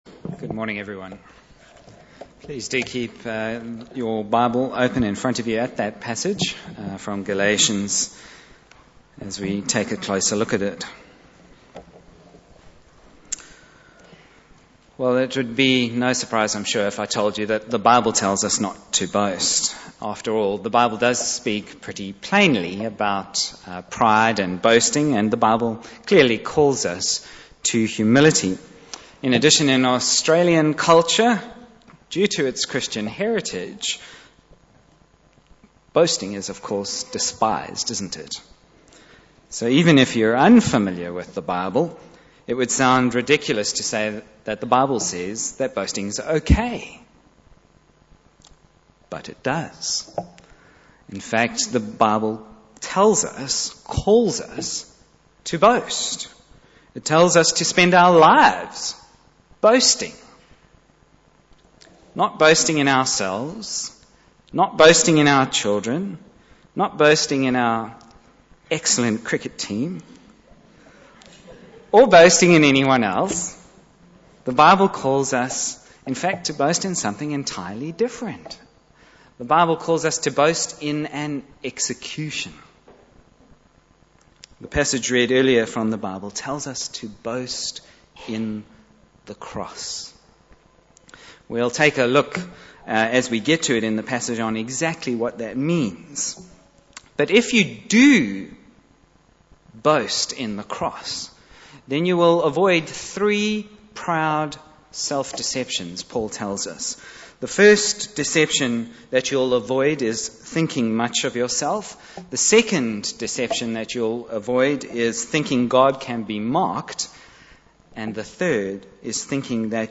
Bible Text: Galatians 5:26-6:18 | Preacher